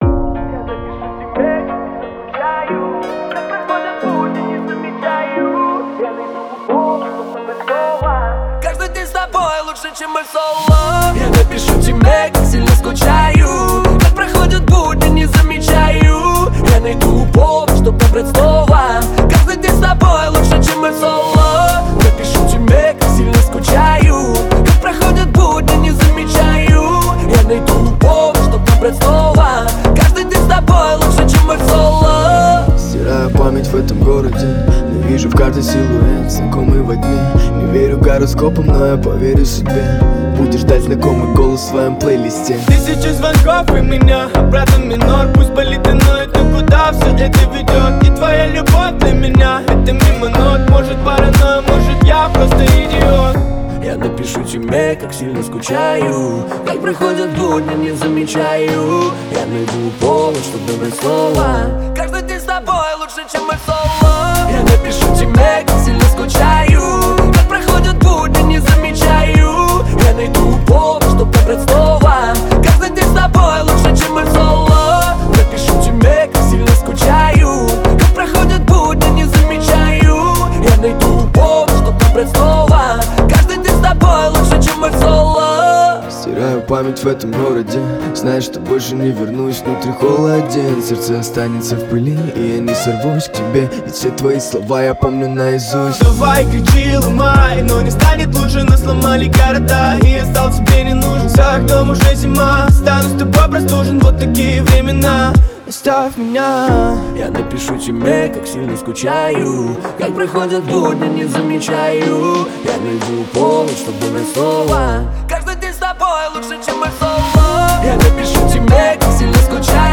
Танцевальная музыка
Dance музыка , танцевальные песни